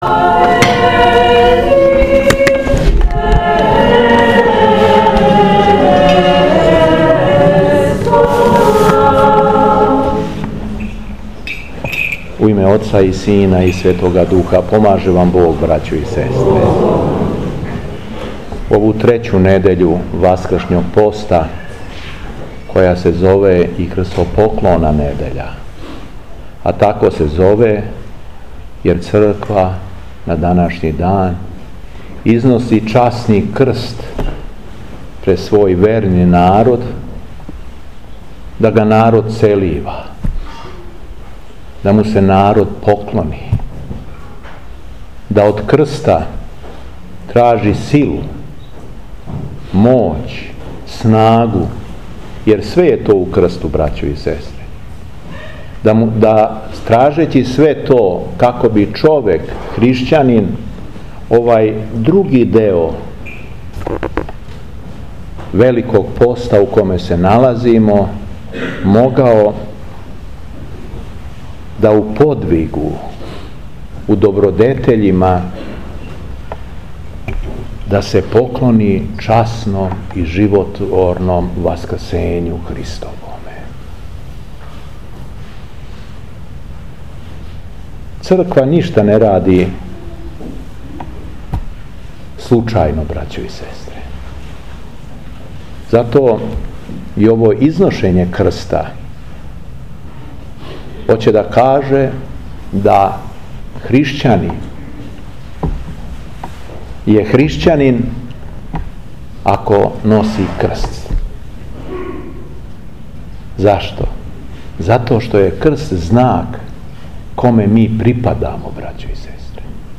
Беседа Епископа шумадијског Г. Јована